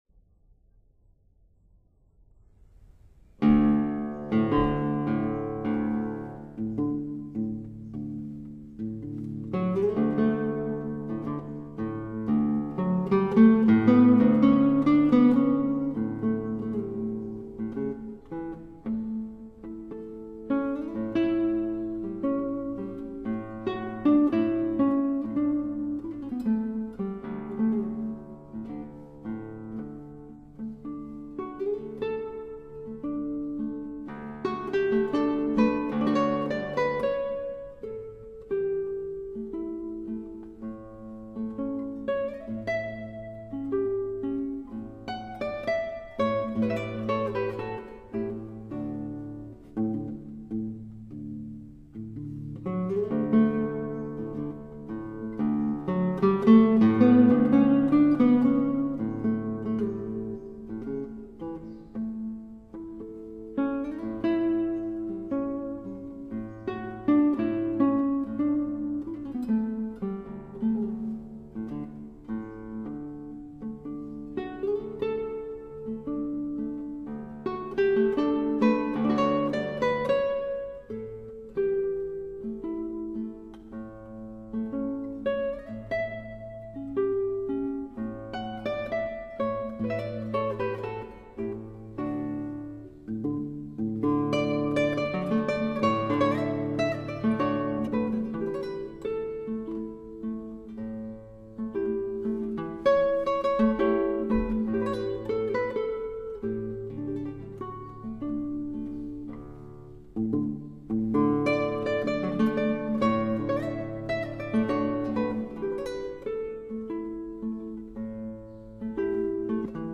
Genre: Classical Guitar